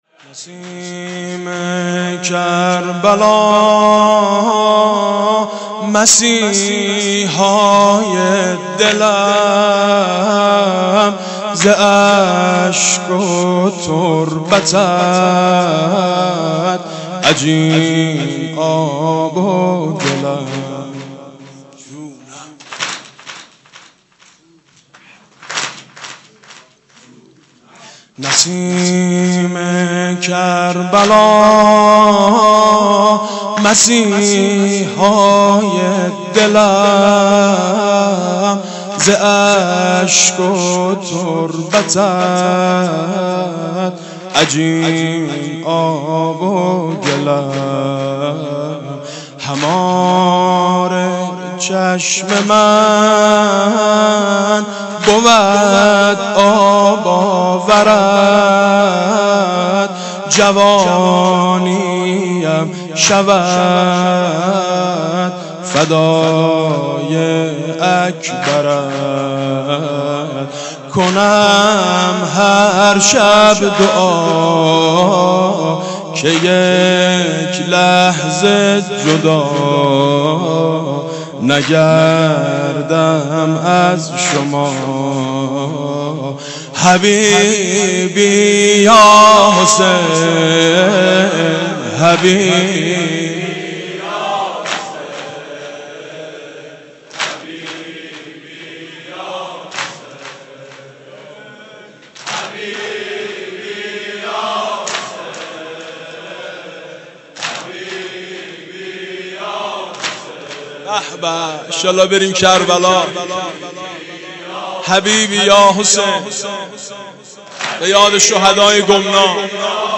«شهادت امام صادق 1390» زمینه: نسیم کربلا مسیحای دلم